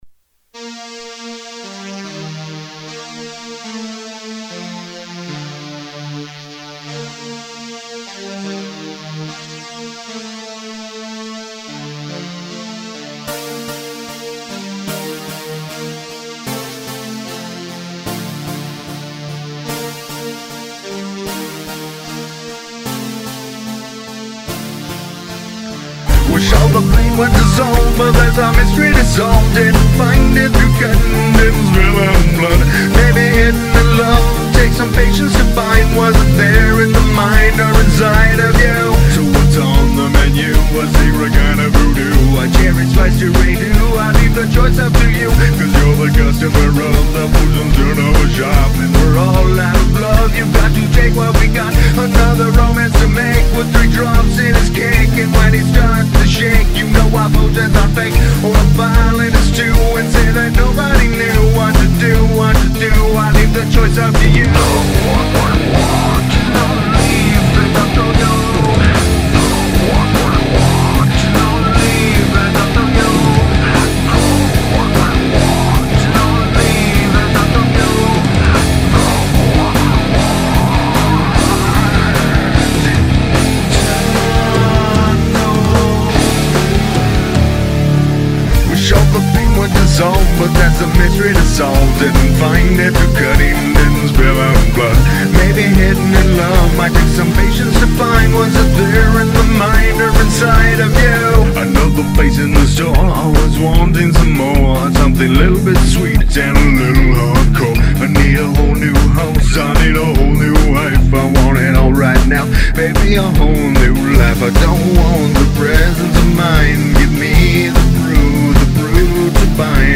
Experimental
Dance Metal... LOL